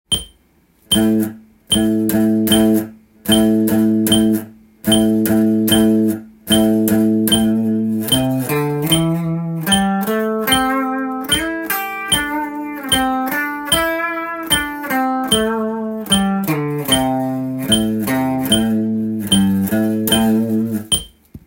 スケールでリズム練習tab
①のリズムは４分音符と１つと８分音符２つの
カンタンなリズムです。